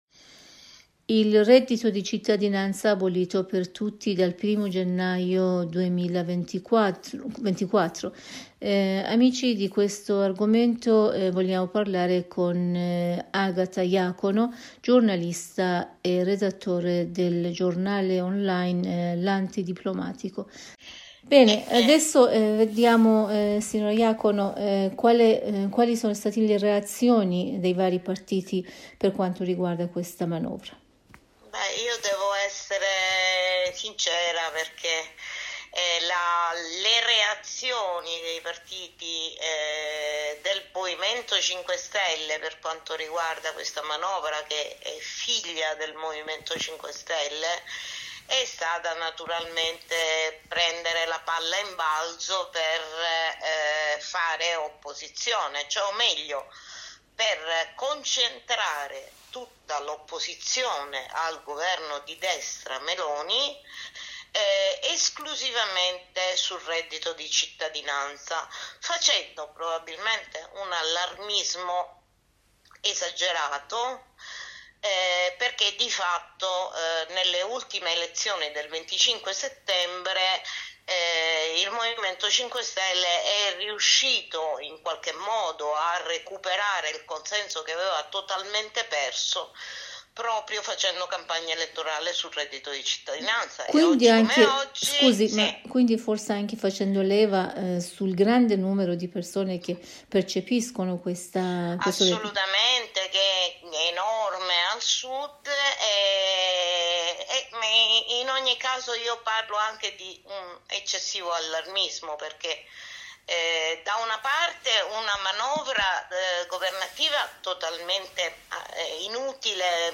sociologo e giornalista che collabora con il giornale online L'antidiplomatico in un collegamento telefonico con la Radio Italia della Voce della Repubblica islamica dell'Iran (IRIB) con il sito http